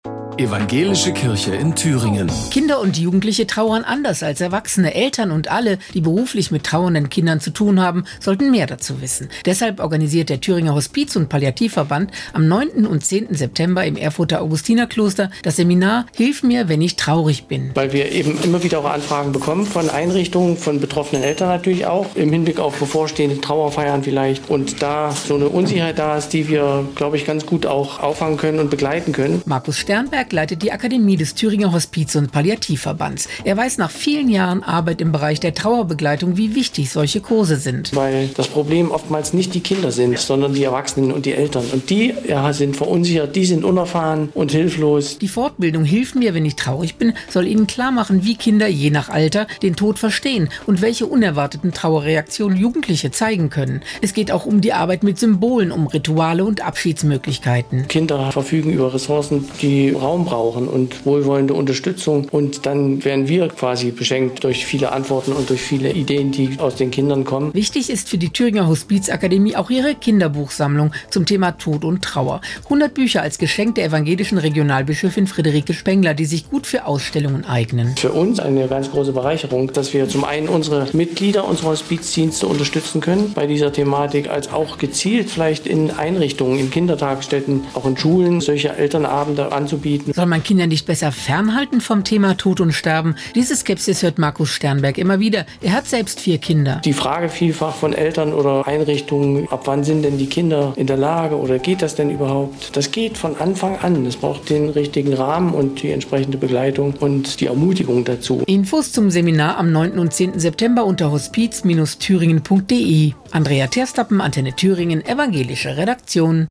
Radiobeitrag hören